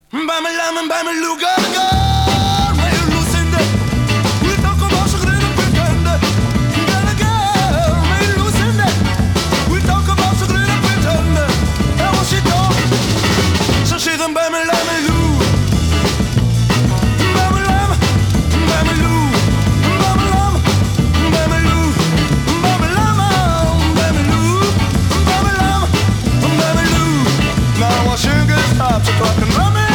R'n'b